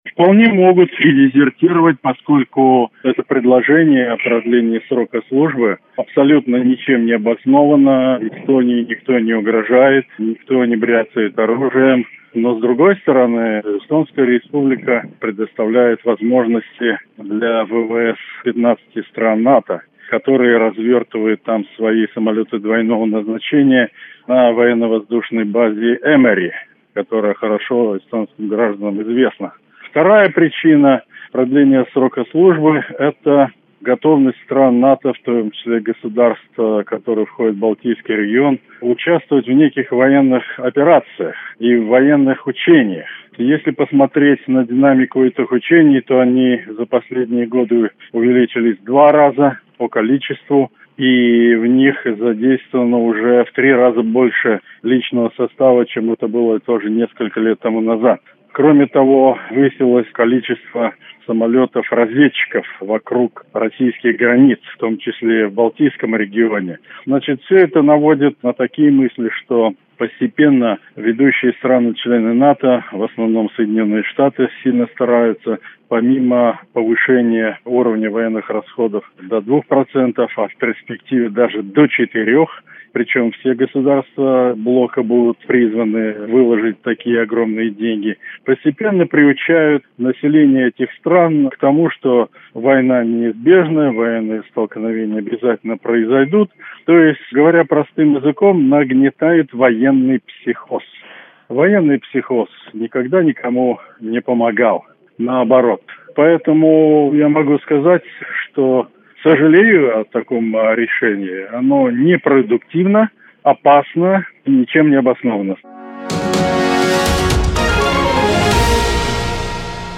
Источник:  радио Sputnik